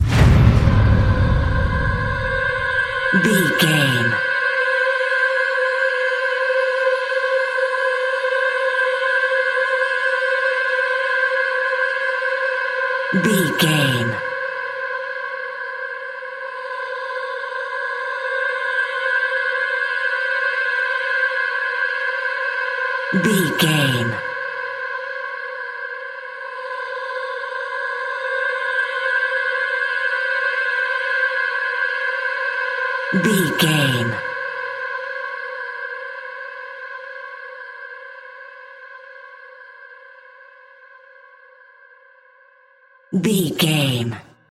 Horror Vocal Hit.
In-crescendo
Atonal
scary
tension
ominous
dark
haunting
eerie
strings
vocals
synth
pads